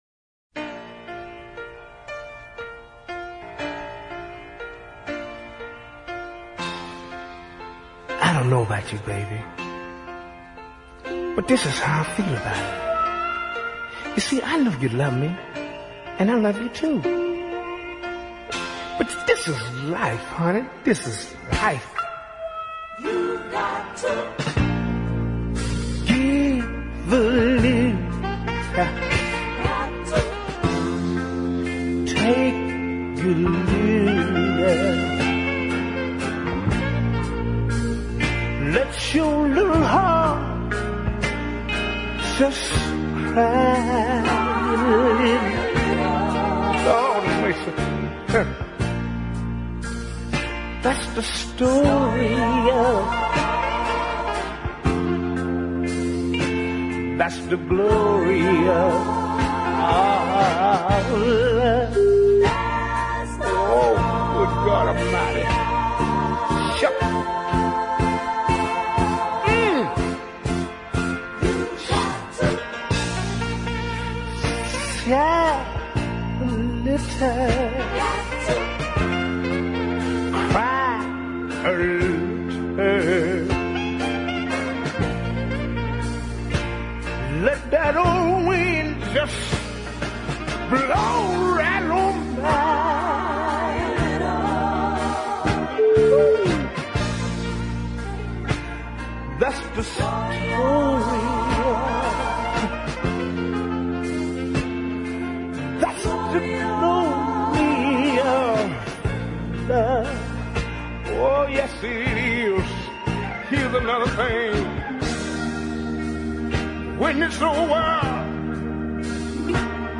a cover of the old warhorse